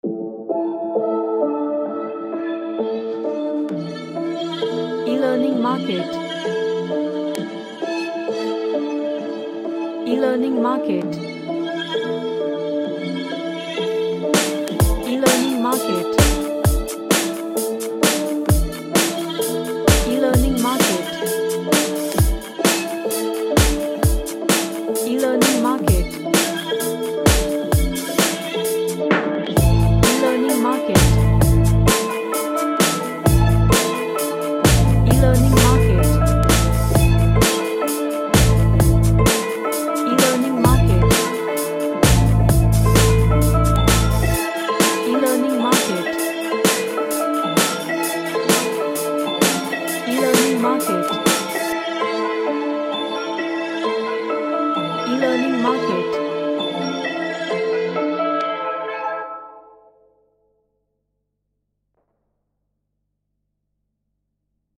A mystical dark sounding R&B track
Magical / Mystical